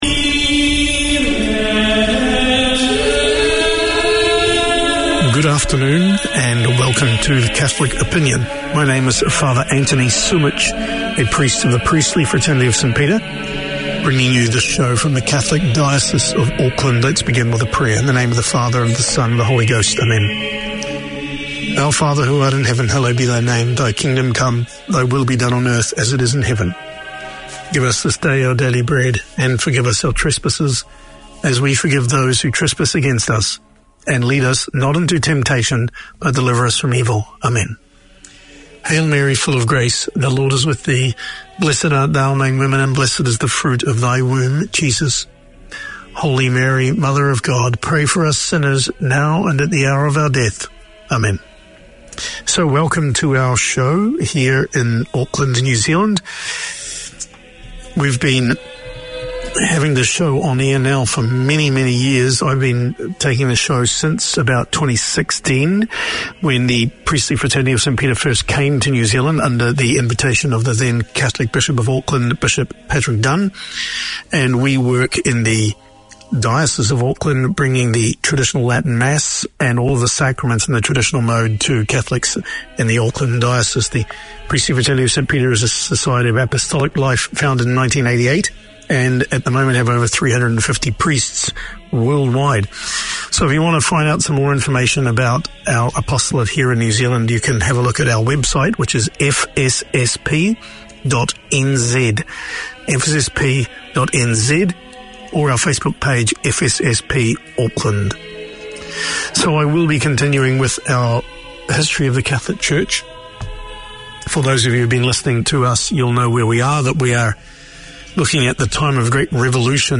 Community Access Radio in your language - available for download five minutes after broadcast.
A comprehensive arts show featuring news, reviews and interviews covering all ARTS platforms: film, theatre, dance, the visual arts, books, poetry, music ... anything that is creative.